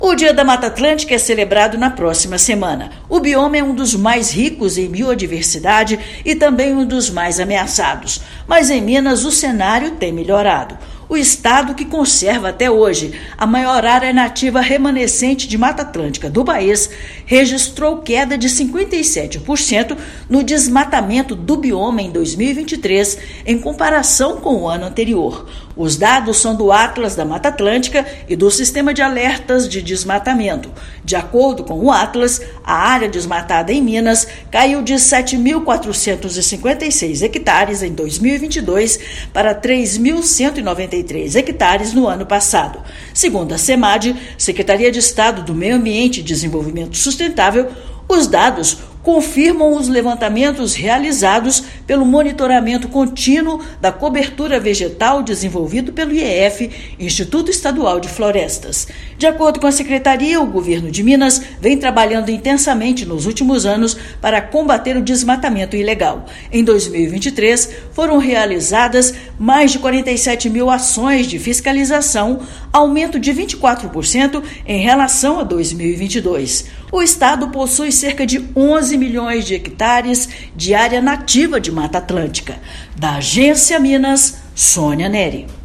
Ações de fortalecimento da fiscalização ambiental e do monitoramento contínuo da vegetação trazem resultado significativo para o estado, que possui a maior área remanescente do bioma. Ouça matéria de rádio.